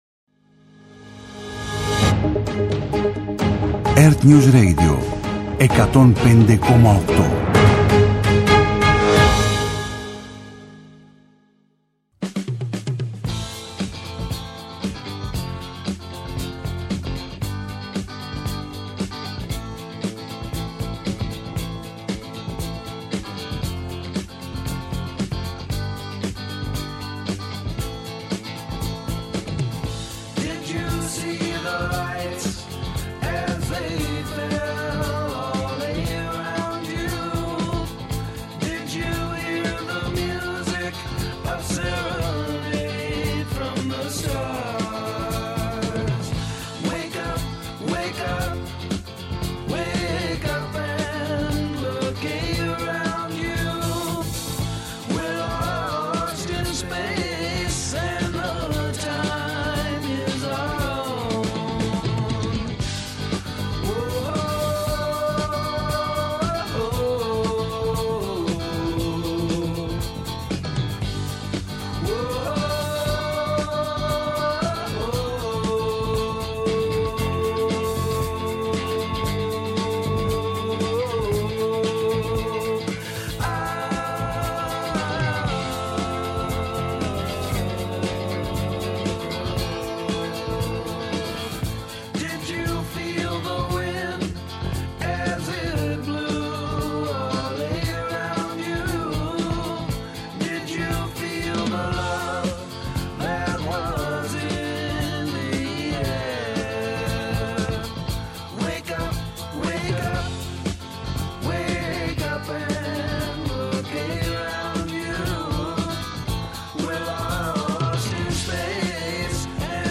Με αναλύσεις, πρακτικές συμβουλές και συνεντεύξεις με πρωτοπόρους στην τεχνολογία και τη δημιουργικότητα, τα «Ψηφιακά Σάββατα» σας προετοιμάζει για το επόμενο update.